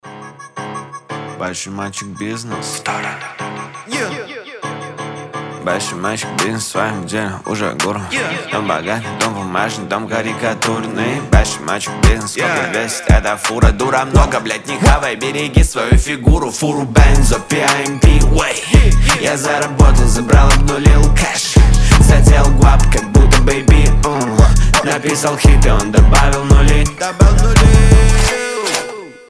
качающие
матерные
Версия с матом